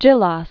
(jĭläs), Milovan 1911-1995.